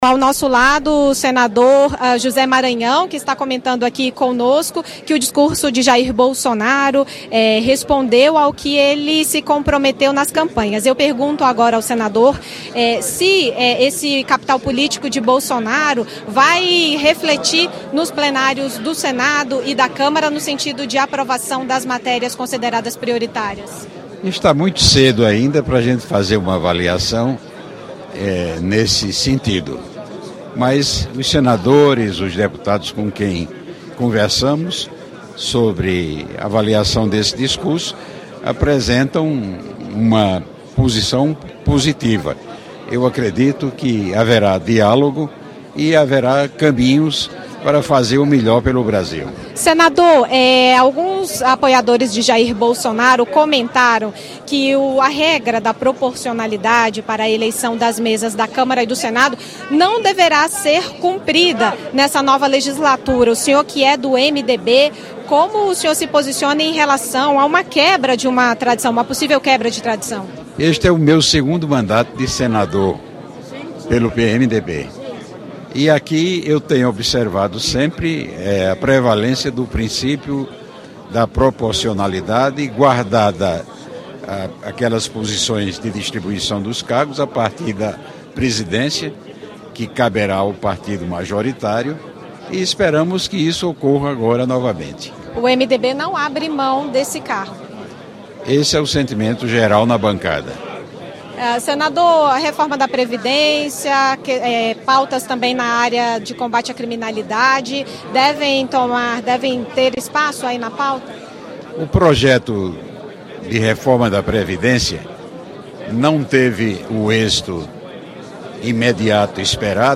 Senador José Maranhão comenta o discurso de posse de Jair Bolsonaro